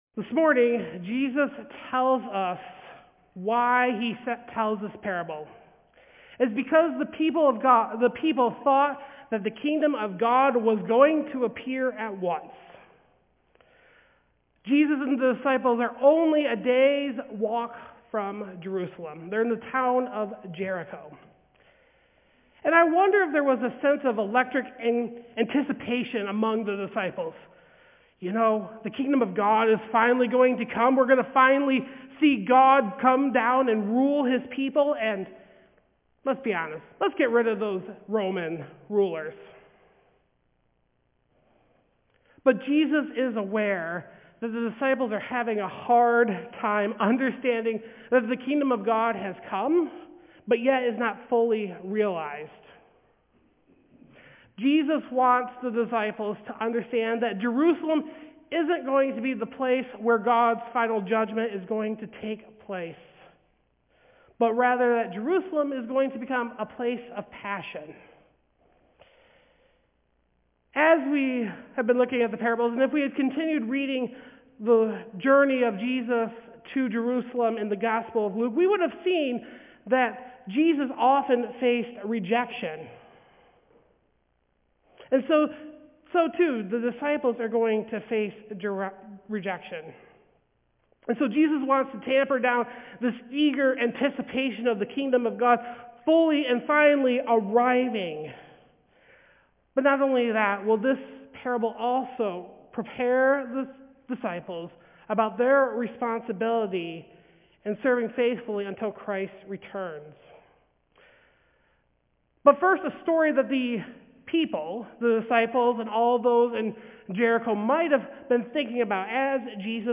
Passage: Luke 19:11-27 Service Type: Sunday Service